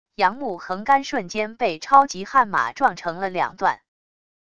杨木横杆瞬间被超级悍马撞成了两段wav音频